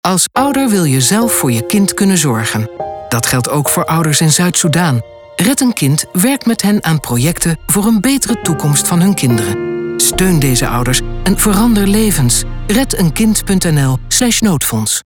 Commercial-Red-Een-Kind-Noodfonds-2023-GNR.mp3